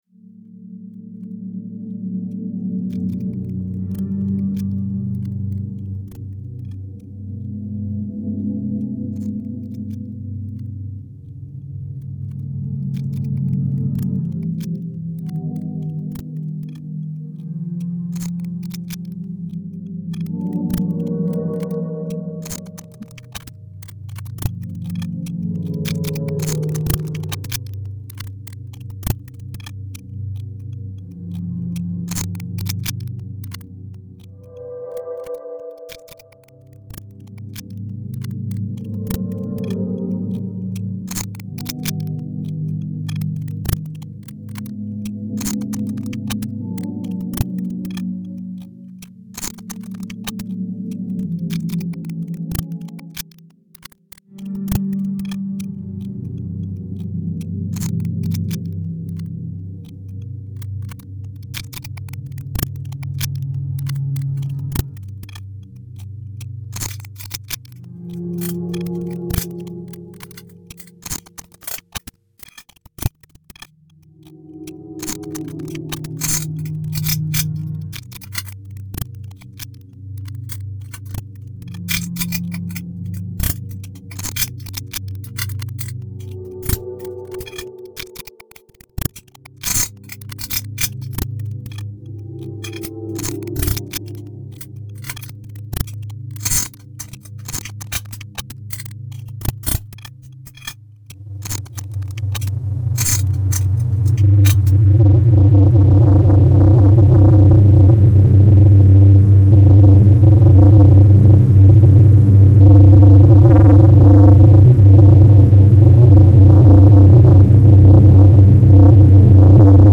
electronics